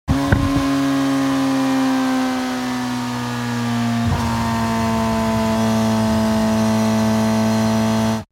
جلوه های صوتی
دانلود صدای موتور 13 از ساعد نیوز با لینک مستقیم و کیفیت بالا
برچسب: دانلود آهنگ های افکت صوتی حمل و نقل دانلود آلبوم صدای موتورسیکلت از افکت صوتی حمل و نقل